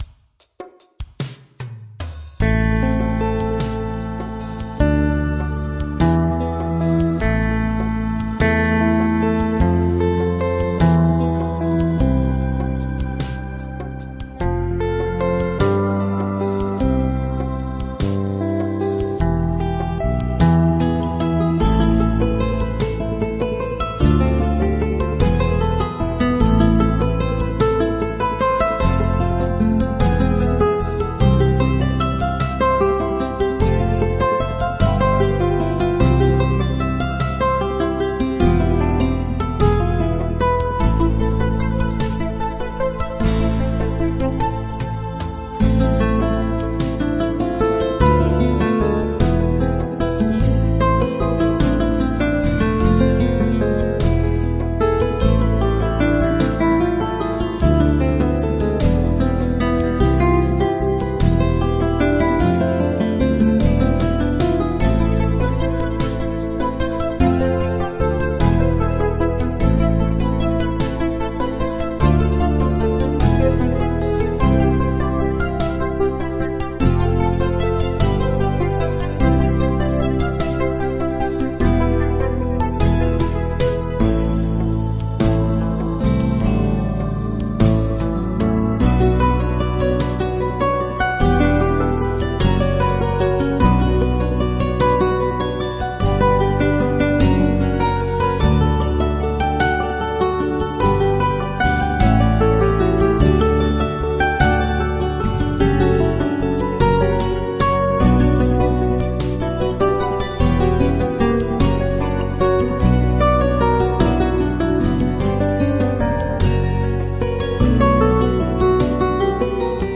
Relaxed Pop Ballad with Classic Guitar Lead